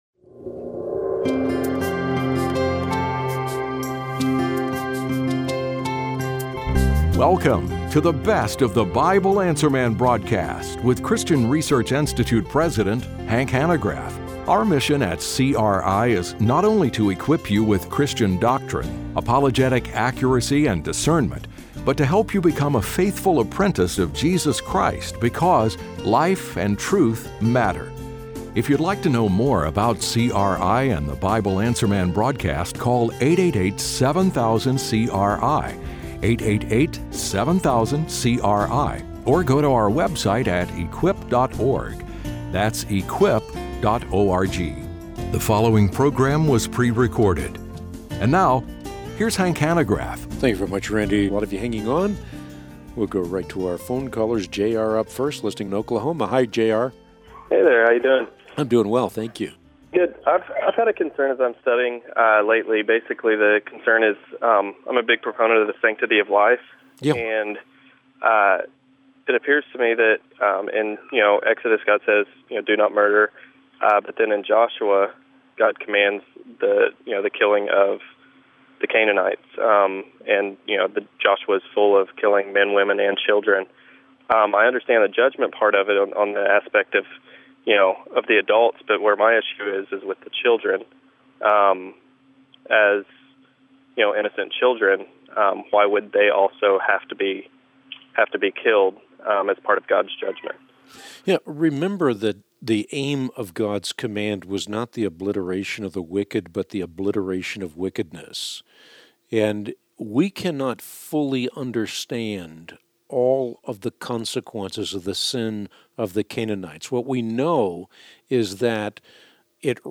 Best of BAM Q&A: Killing of Children, Predestination, and Age of the Earth | Christian Research Institute